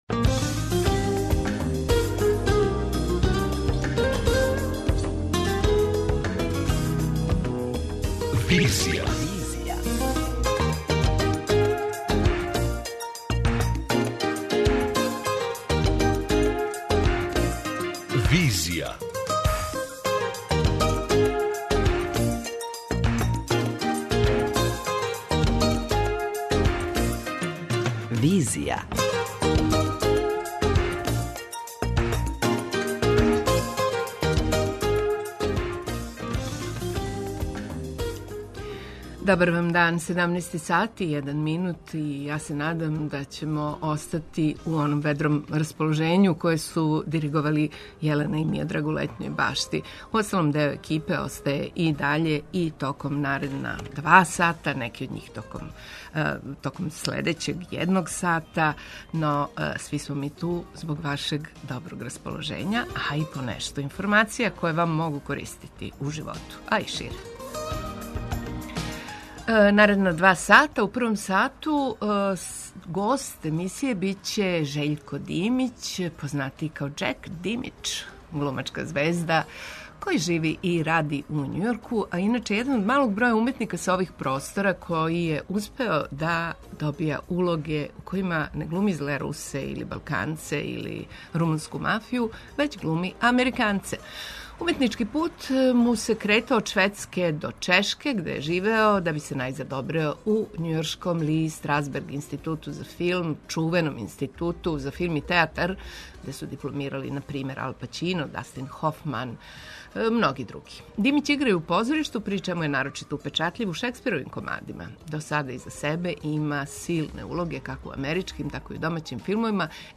Одлазимо телефоном у Загреб, где је синоћ почело шесто издање Фестивала свјетске књижевности.